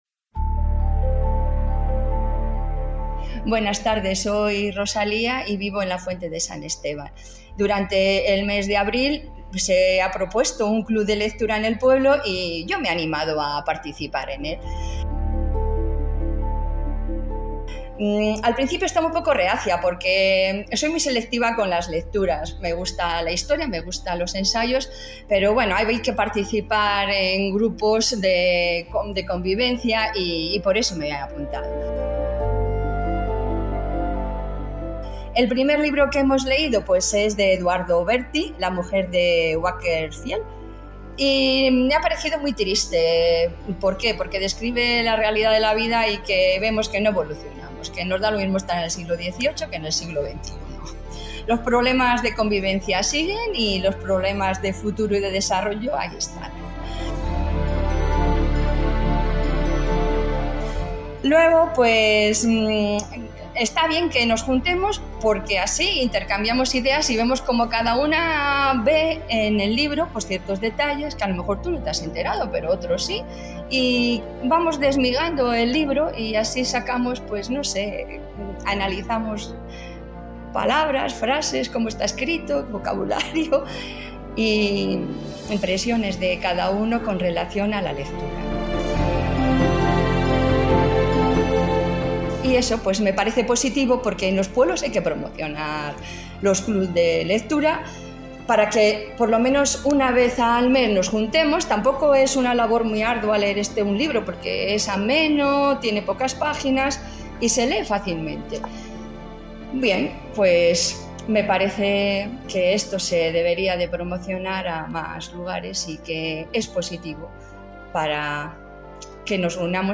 Una componente de esta agrupación cultural ofrecerá, cada cierto tiempo, su punto de vista sonoro sobre una obra literaria